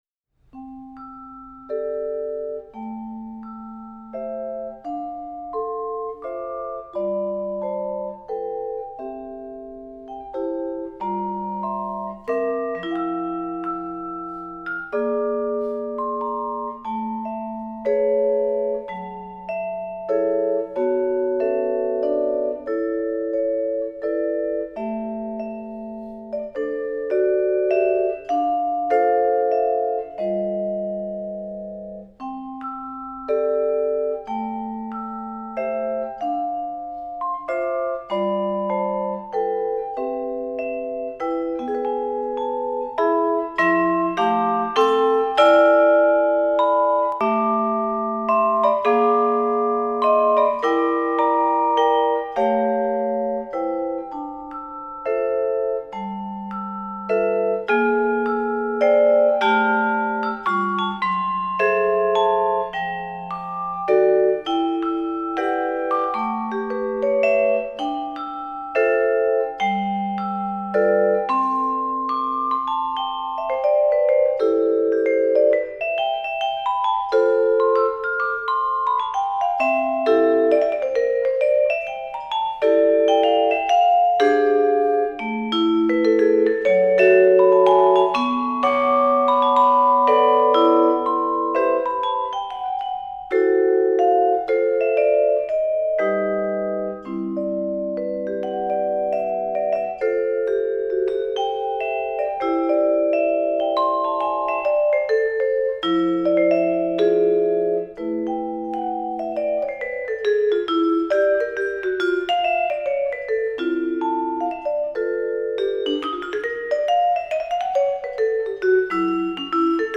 Voicing: Vibe Solo